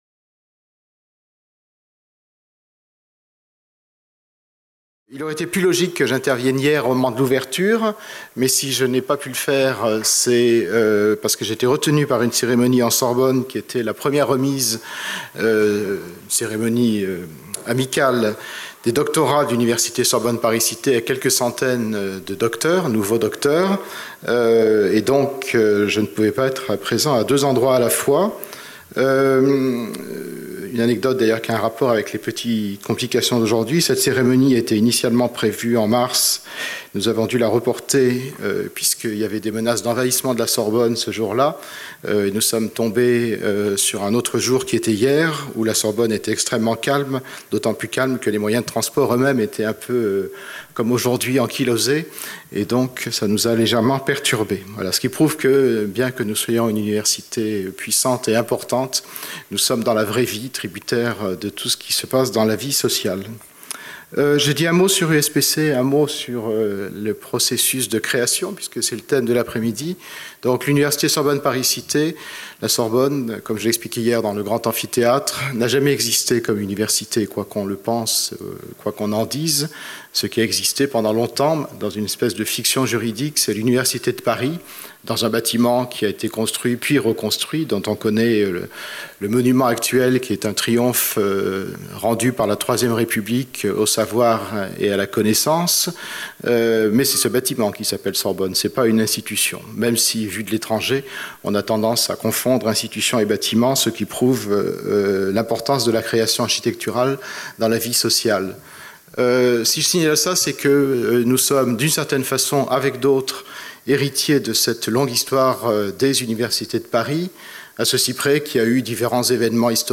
(Re) créer le monde - 19 mai - Processus de création / Introduction et lecture d’extraits de « La musique inconnue » (José Corti Editions) | Canal U
Philharmonie, salle de conférence 14h15 Processus de création